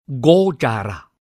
楞伽经梵音词汇读诵001-010